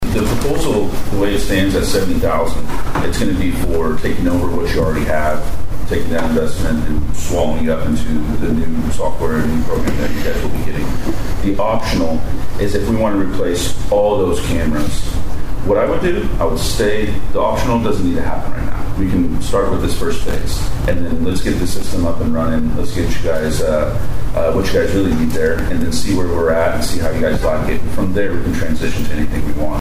At Monday's Osage County Commissioners meeting, board members got a presentation about a presentation regarding a new video surveillance system.